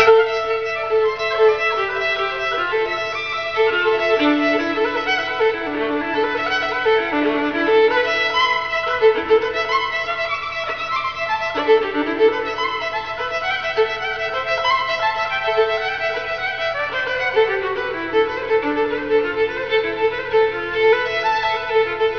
for solo violin
violin
Recorded May 9, 1995 at Marsh Chapel, Boston University